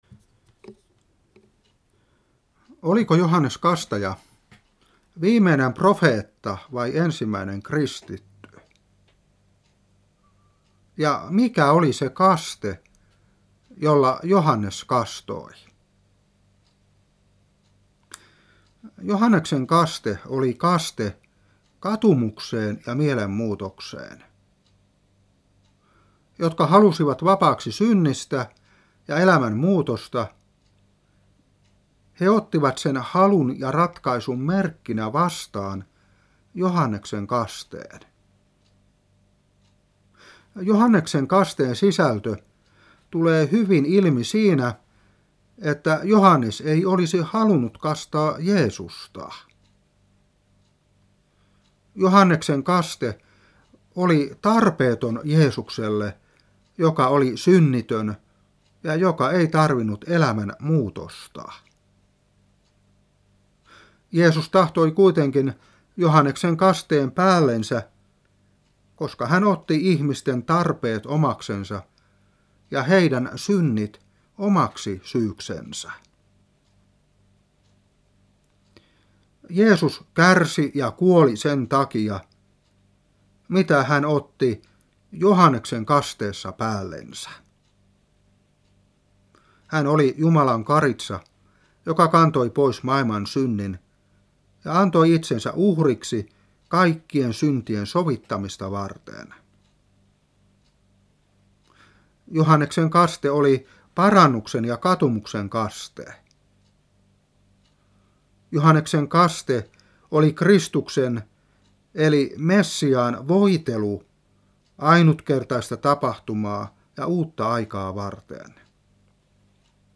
Opetuspuhe 2018-6. Matt.3:4-6,13-15.